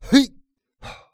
XS瞬间起身1.wav
XS瞬间起身1.wav 0:00.00 0:01.13 XS瞬间起身1.wav WAV · 97 KB · 單聲道 (1ch) 下载文件 本站所有音效均采用 CC0 授权 ，可免费用于商业与个人项目，无需署名。
人声采集素材